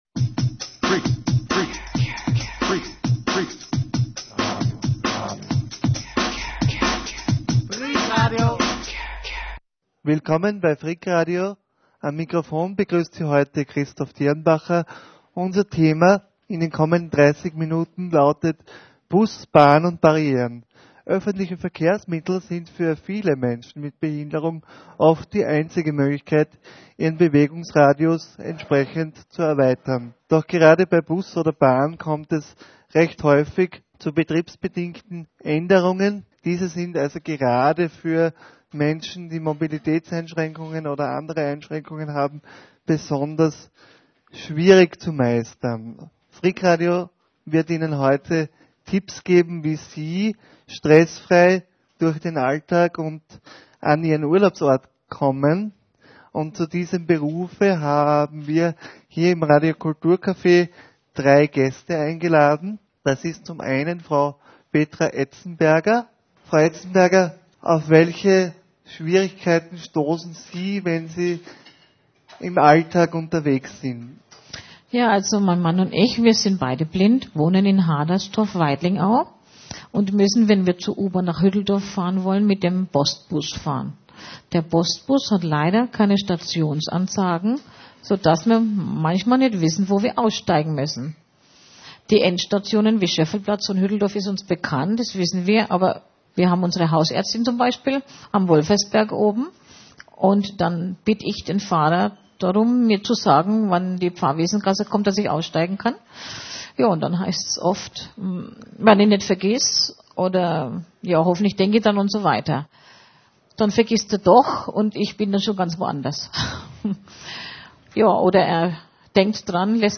Doch die Praxis zeigt: Es ist noch längst nicht "alles" barrierefrei. Hören Sie eine aktuelle Diskussion aus dem Radio Kulturcafé.